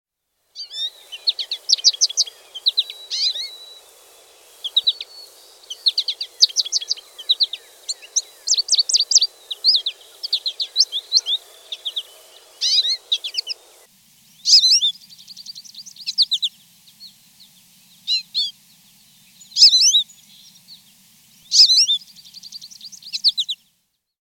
Goldfinch
goldfinch.mp3